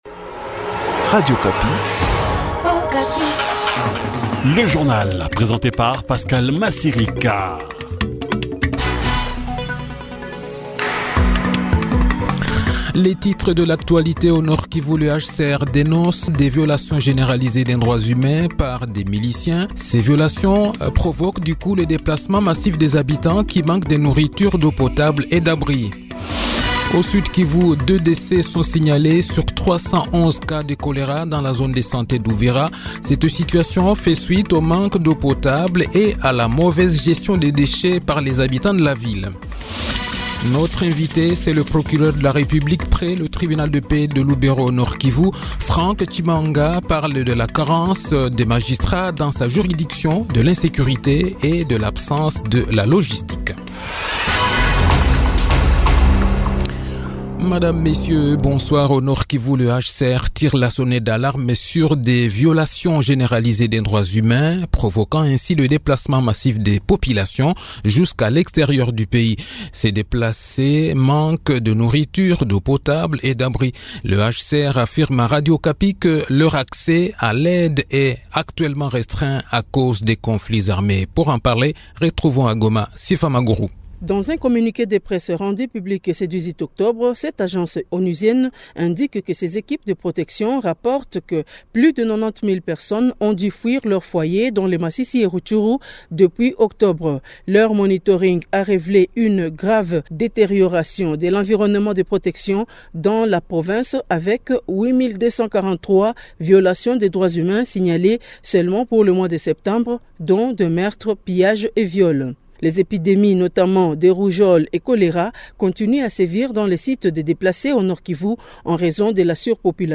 Le journal de 18 h, 19 octobre 2023